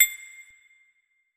Bell [Metro].wav